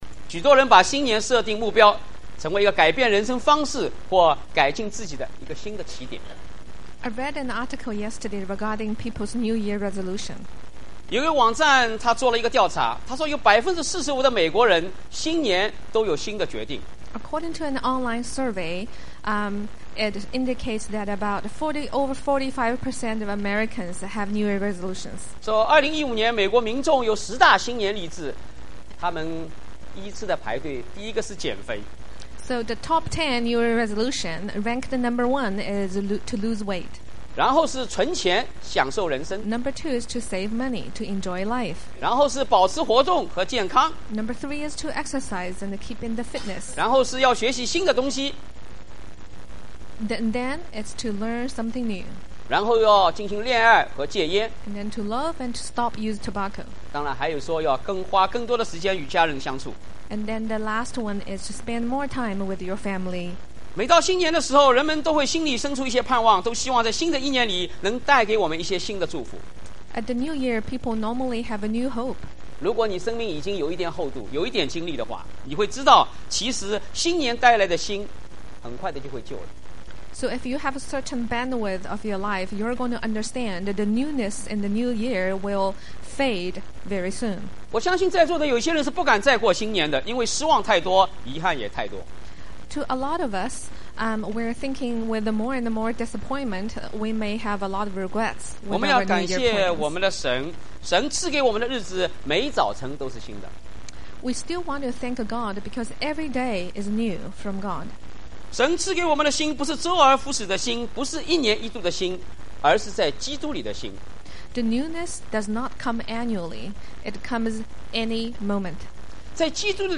牧師 應用經文: 《 馬太福音》6：9～10